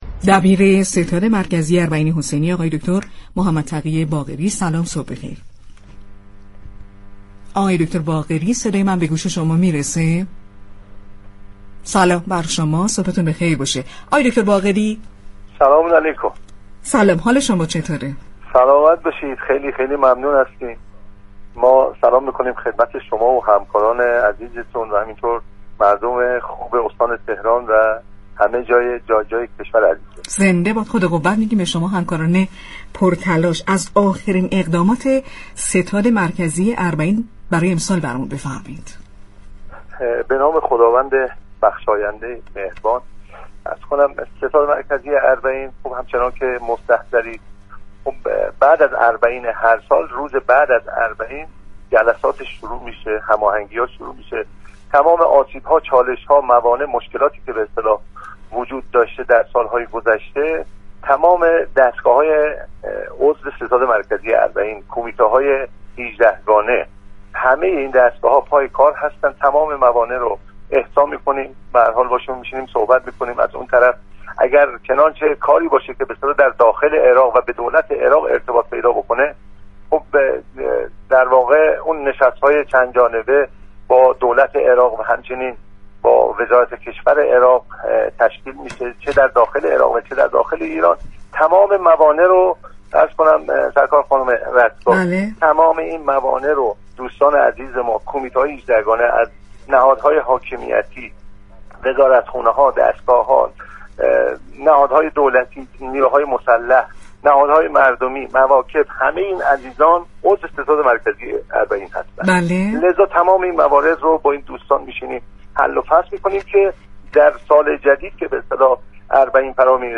دریافت فایل به گزارش پایگاه اطلاع رسانی رادیو تهران، محمدتقی باقری در ارتباط زنده تلفنی با برنامه "صبح نو،تهران نو" با اشاره به برپایی نشست‌های چند جانبه با دولت عراق و رفع موانع پیش رو توسط كمیته‌های 18 گانه، وزارتخانه‌ها، دستگاه‌ها، مواكب و نیروهای مسلح تاكید كرد: زیرساخت‌های حمل ونقل برقرار است و كادر درمان ایران در مرزها و هلال احمر در داخل عراق حضور دارند.